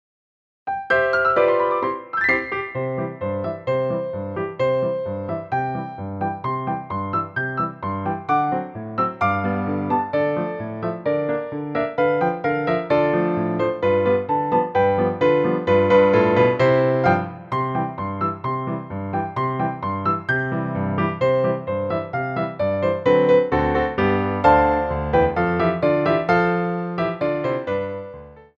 for Ballet Class
Quick Tendus
2/4 (16x8)